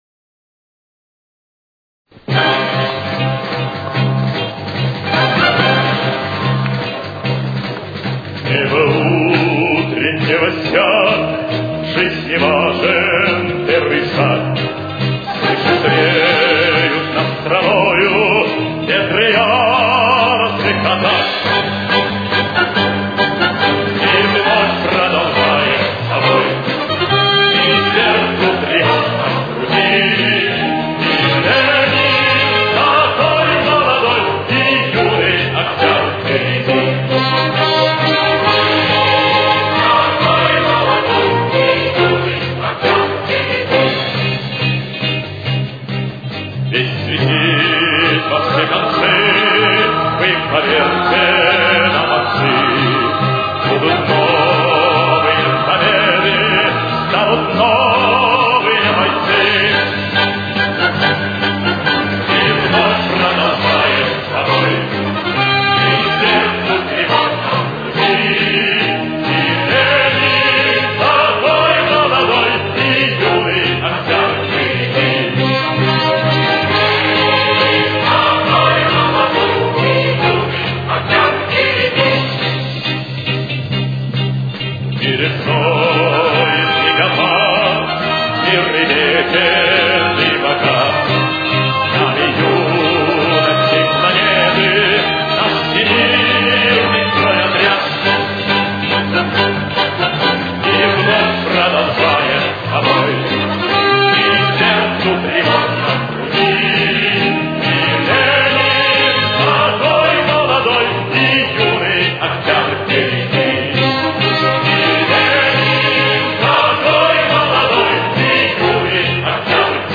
До минор. Темп: 141.